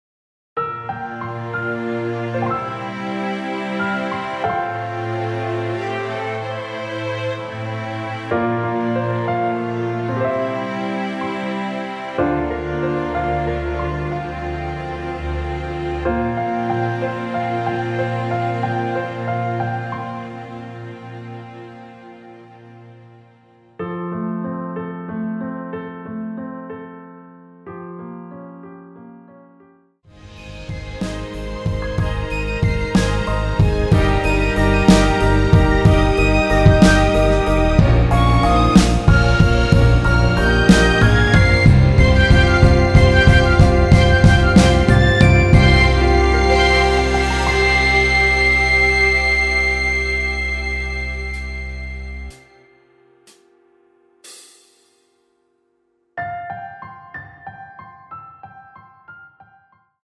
3분 14초경 무반주 후 노래 들어가는 부분 박자 맞추기 쉽게 카운트 추가하여 놓았습니다.(미리듣기 확인)
원키에서(+4)올린 MR입니다.
앞부분30초, 뒷부분30초씩 편집해서 올려 드리고 있습니다.